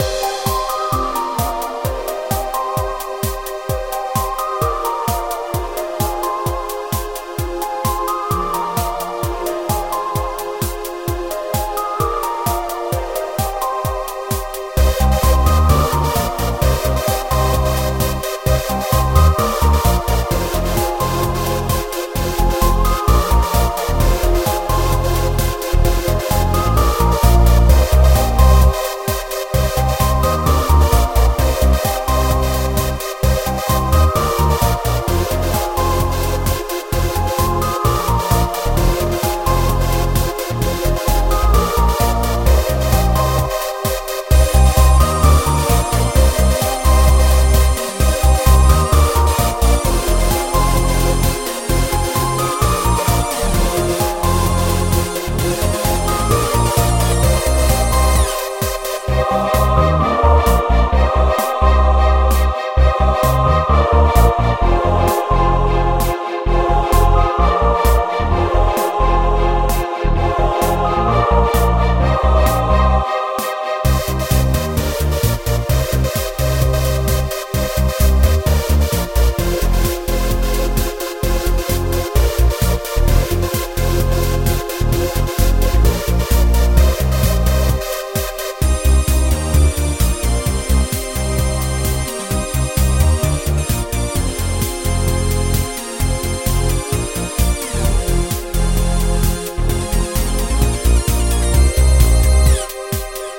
happy
cyber